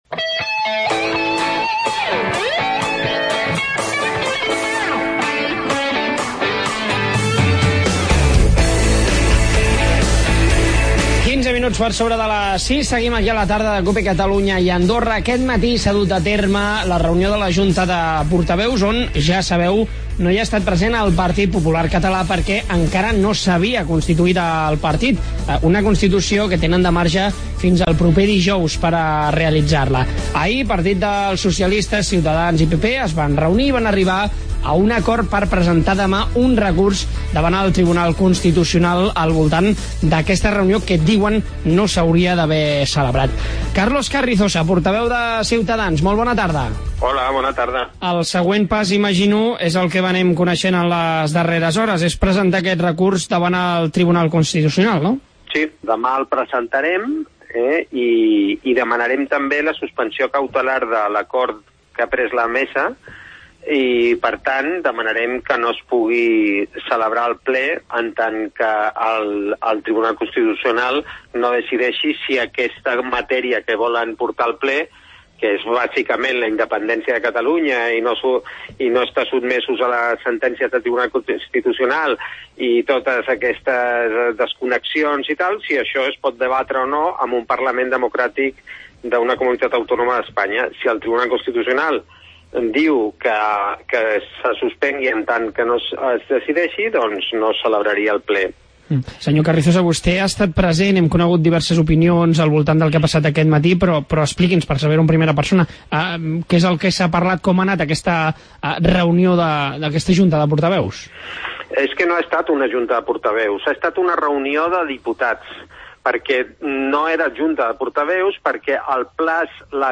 Avui diversos partits de l'oposició han presentat un recurs al TC contra la moció independentista. Ens ho ha explcat en Carlos Carrizosa, portaveu de Ciutadans